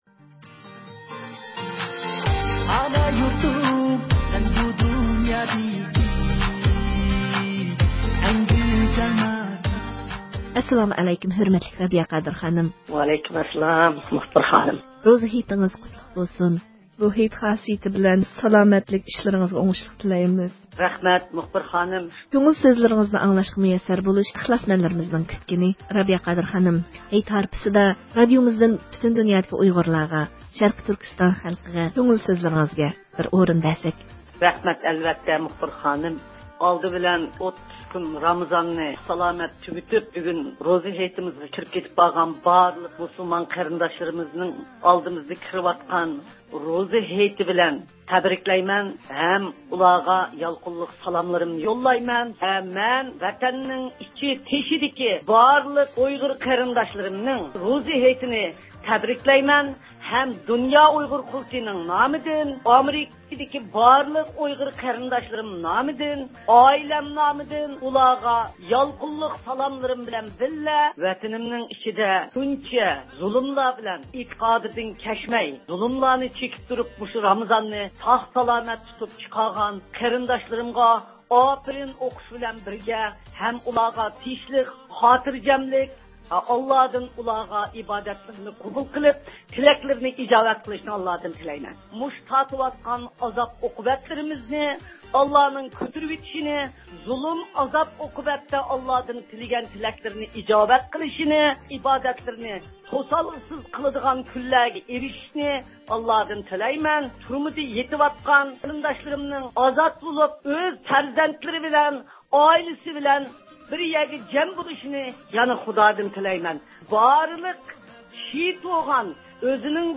ئاۋاز ئۇلىنىشى ئارقىلىق مۇخبىرىمىز رابىيە قادىر خانىمنىڭ روزا ھېيتلىق ئامانىتىنى سىزلەرگە تاپشۇرىدۇ.